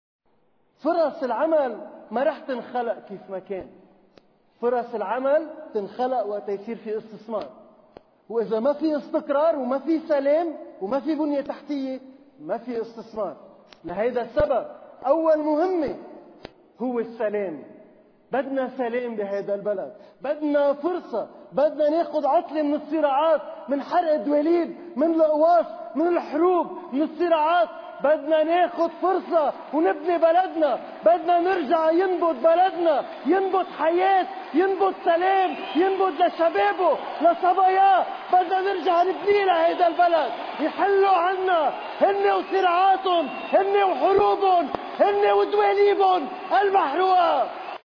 النائب سامي الجميل في حفل إطلاق المكنة الإنتخابية لحزب الكتائب اللبنانية، في “فوروم دو بيروت: (4 شباط 2018)
سامي-الجميل-اطلاق-المكنة-الإنتخابية.mp3